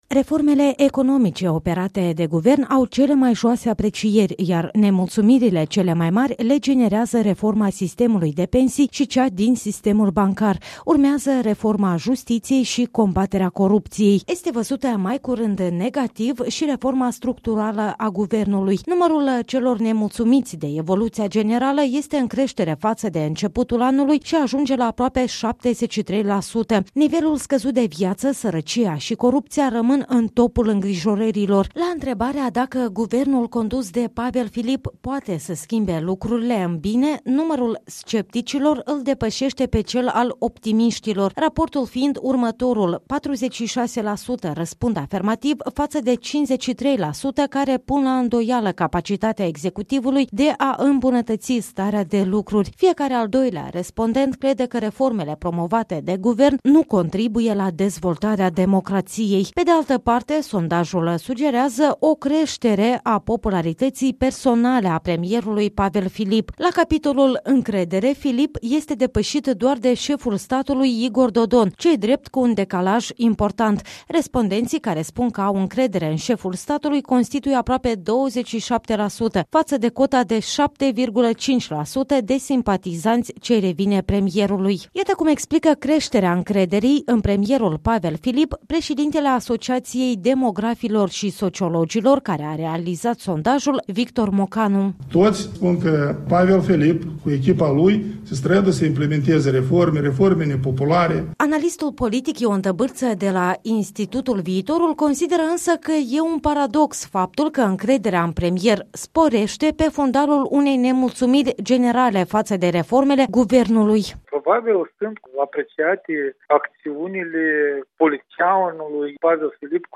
Cele mai importante concluzii ale sondajului sunt rezumate în relatarea corespondentei noastre.